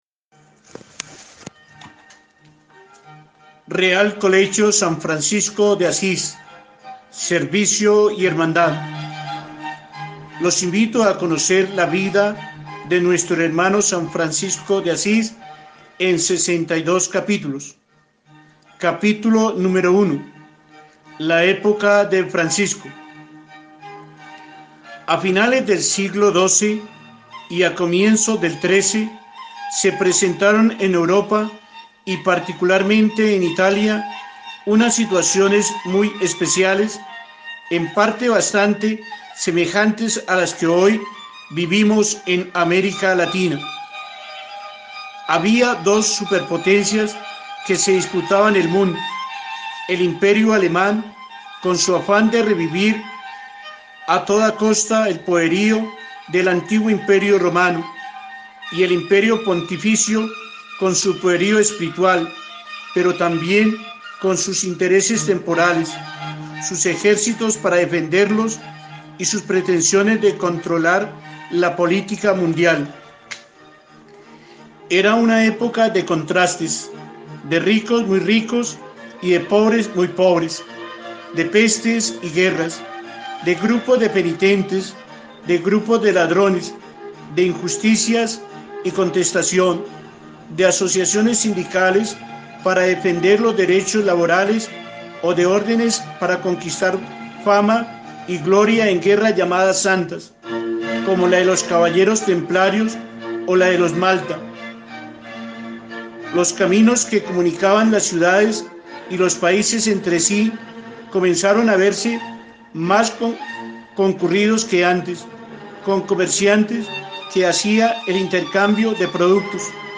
Himno
Letra y Música de: Juan Bautista Ospina